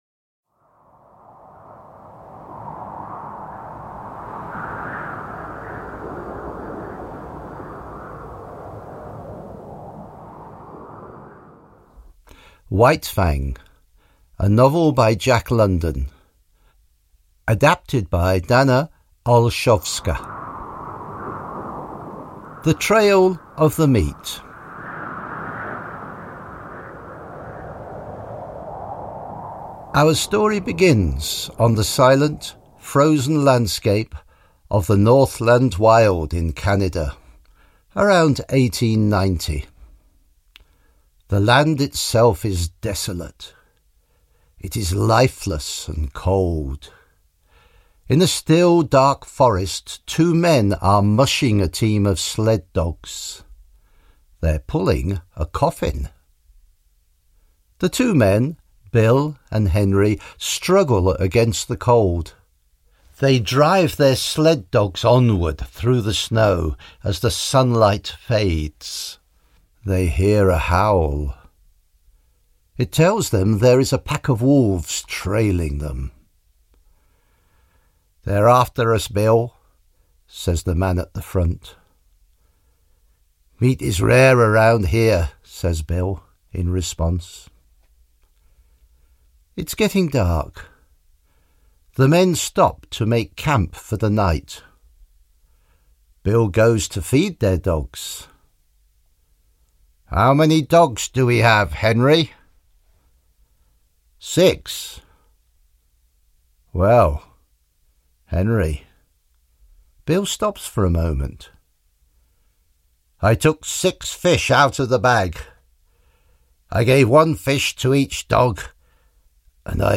Ukázka z knihy
Text v angličtině namluvil rodilý mluvčí.